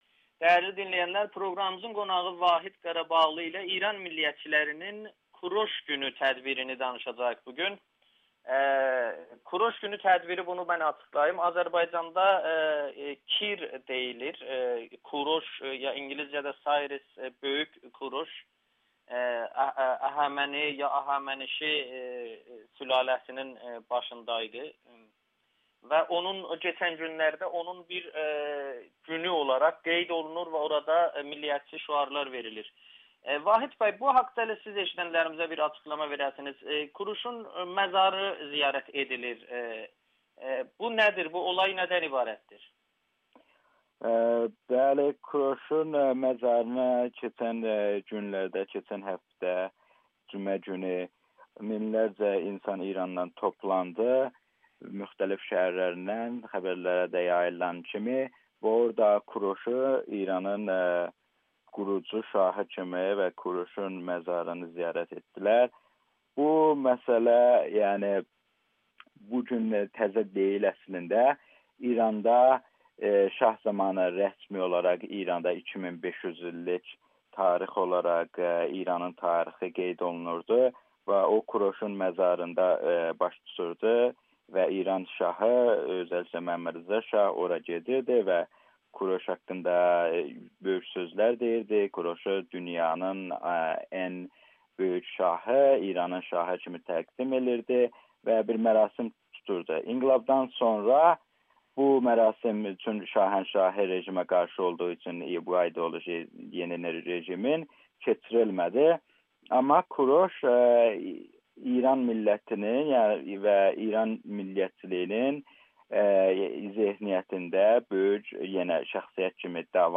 Farsdilli media Kuroş Günü tədbirindəki nifrət ədəbiyyatını görməzdən gəldi [Audio-Müsahibə]